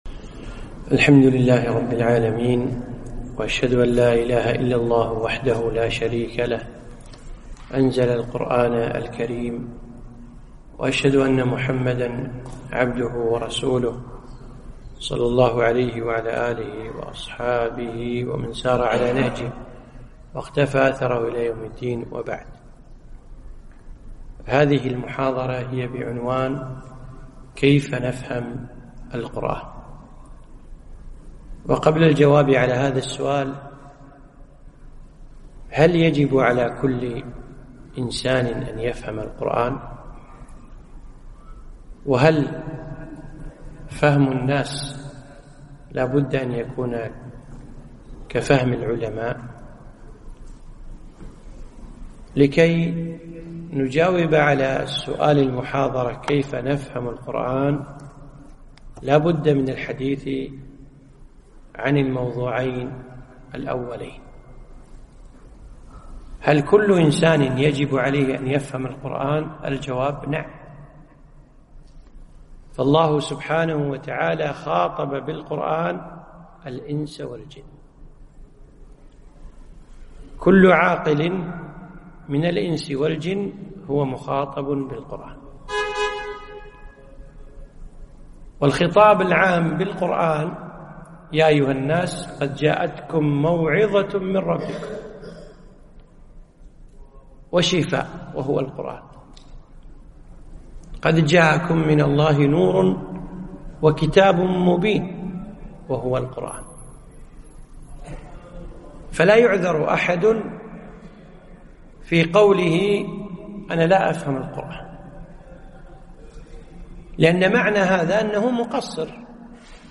محاضرة - كيف نفهم القرآن ؟